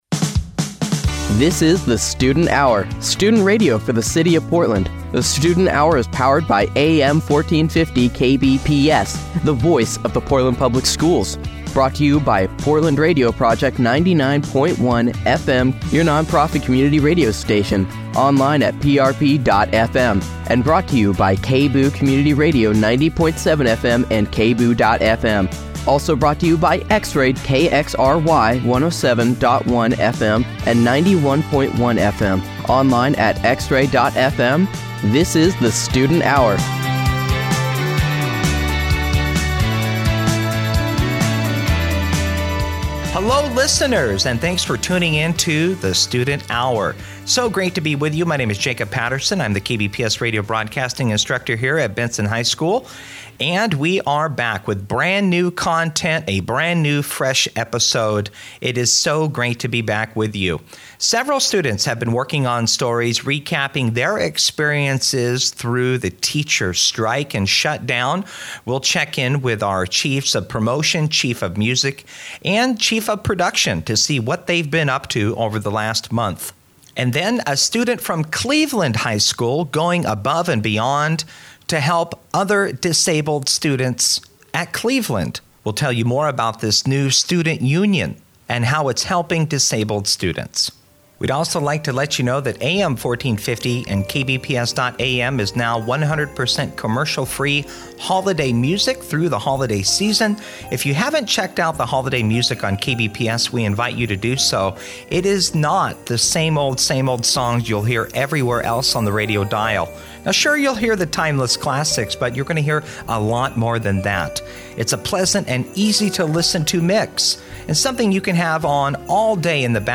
LIVE IN-STUDIO INTERVIEW & PERFORMANCE